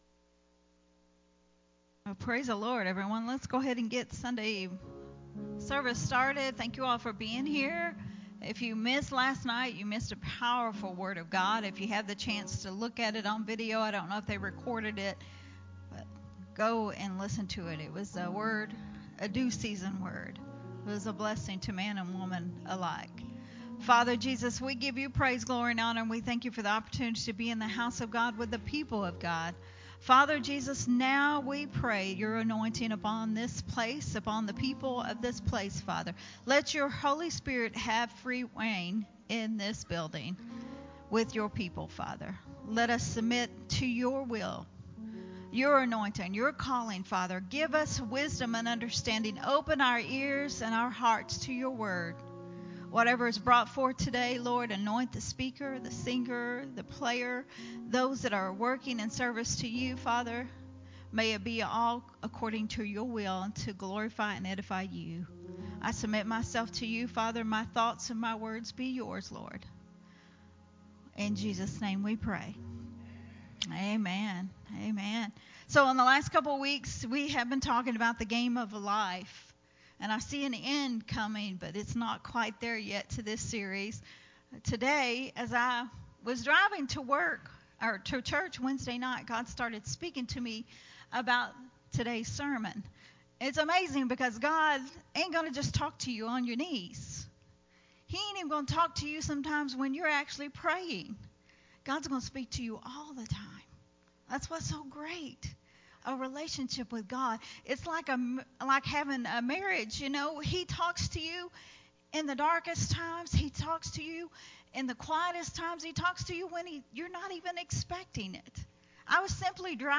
Sunday Morning Refreshing